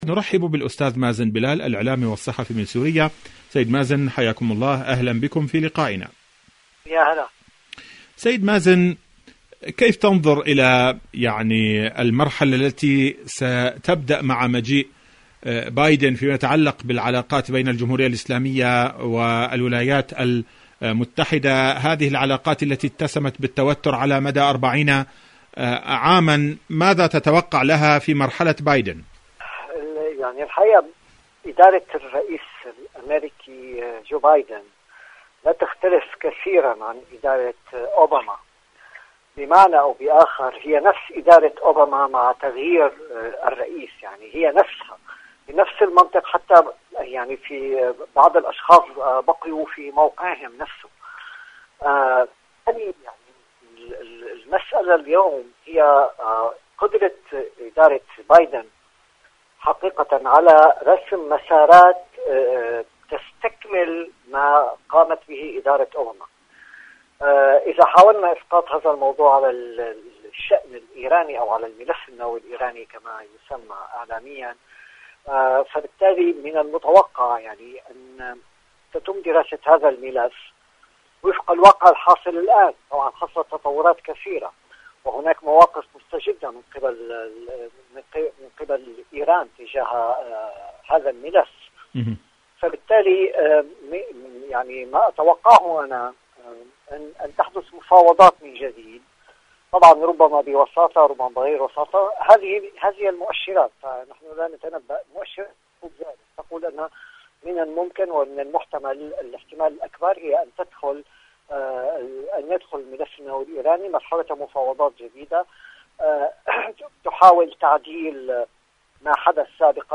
إذاعة طهران-إيران اليوم المشهد السياسي: مقابلة إذاعية